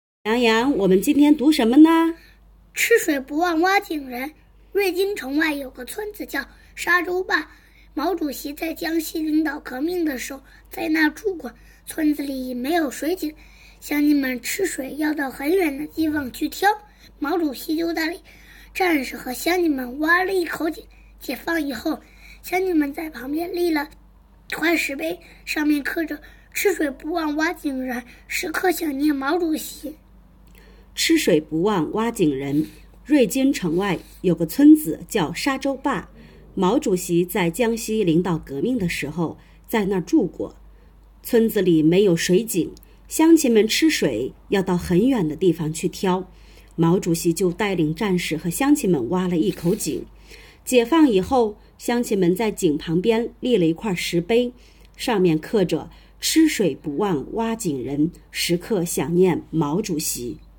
朗诵获奖作品：